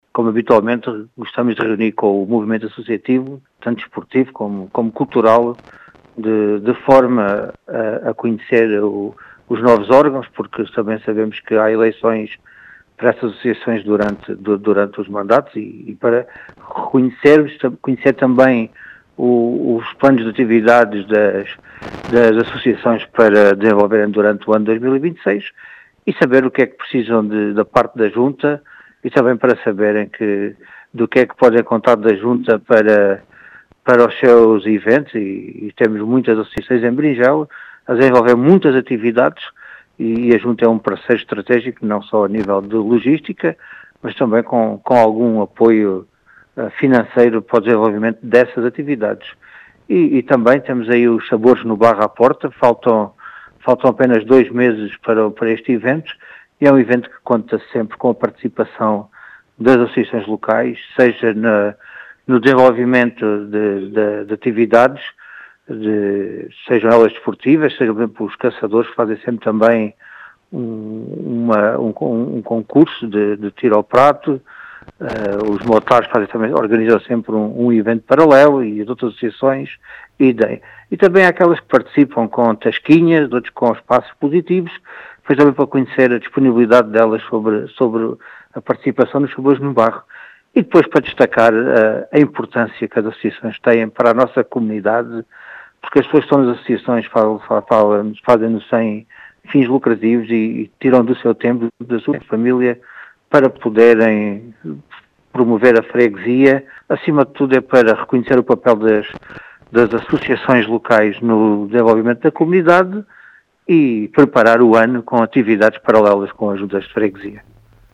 As explicações são do presidente da junta de freguesia de Beringel, Vitor Besugo, que realça a importância do movimento associativo na comunidade.